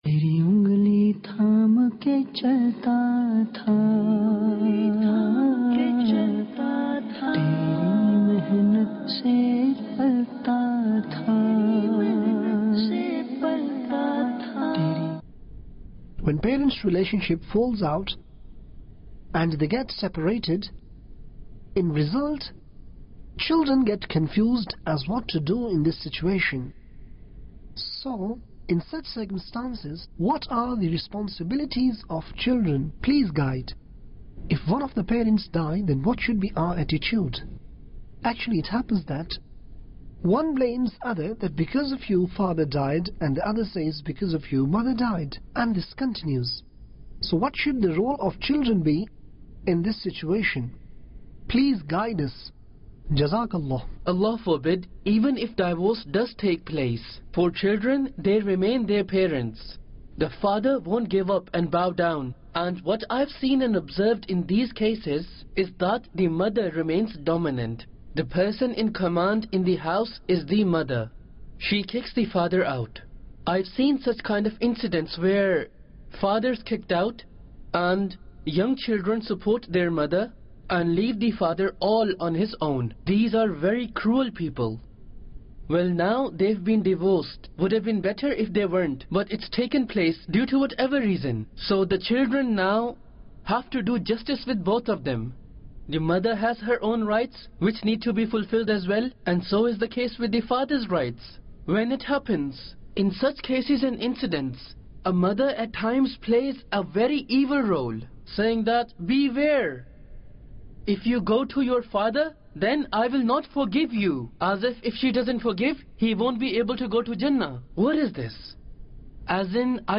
What Should Children Do About Personal Disagreements BetweenFather And Mother? - English Dubbing Jul 2, 2021 MP3 MP4 MP3 Share What Should Children Do About Personal Disagreements BetweenFather And Mother?